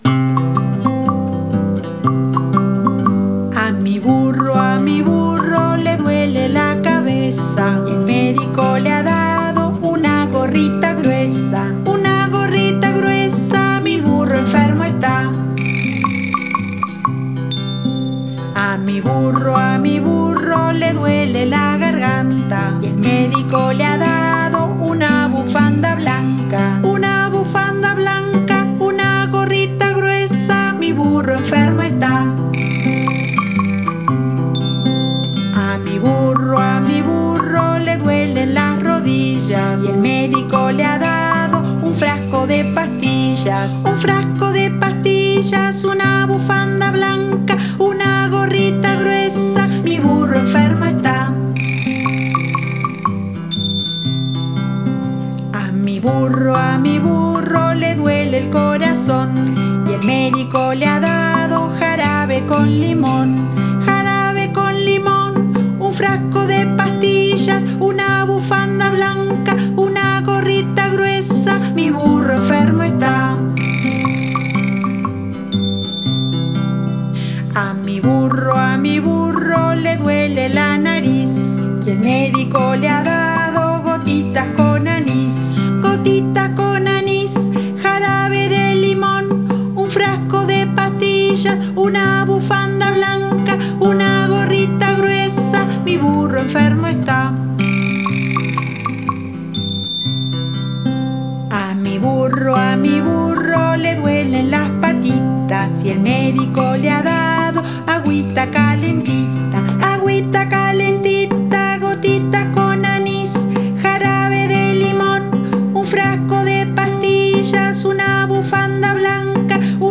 Canción acumulativa